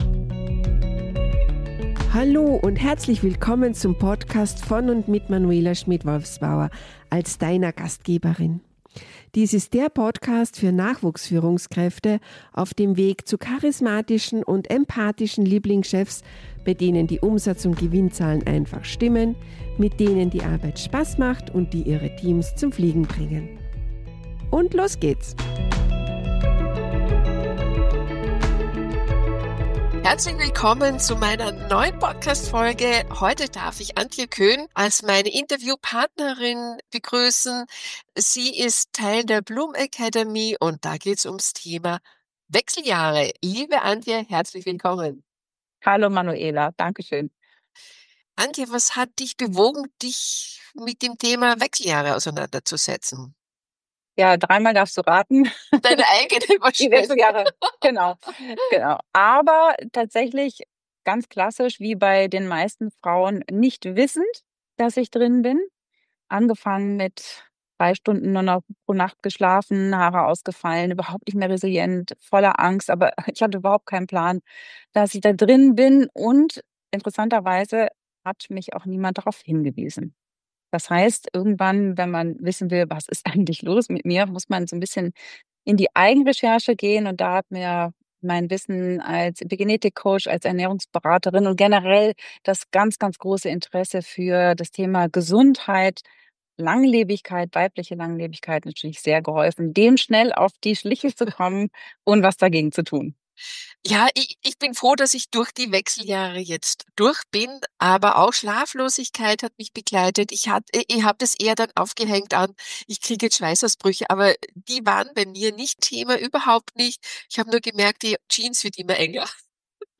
Wie Wechseljahre den Arbeitsalltag beeinflussen, warum Unternehmen das Thema nicht länger tabuisieren dürfen und wie Führungskräfte aller Altersstufen sensibel und unterstützend agieren können, erfährst Du in diesem Interview.